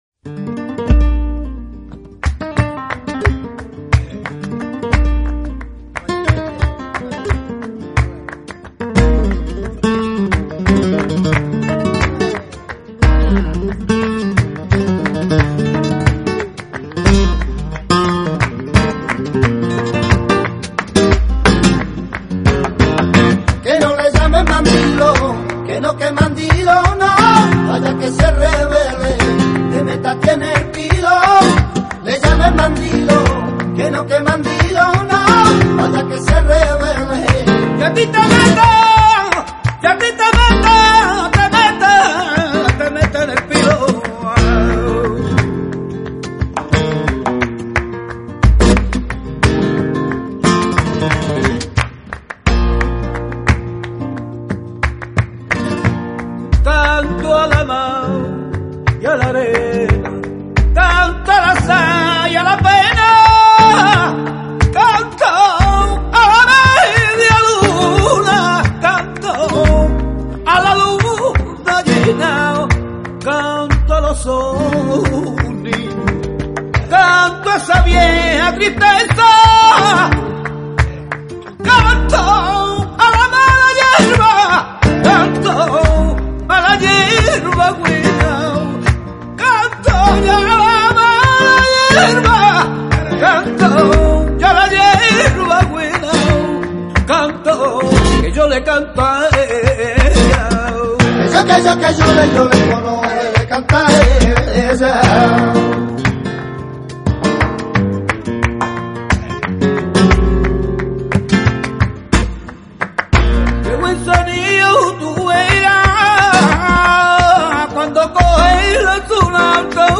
guitar sets a spell.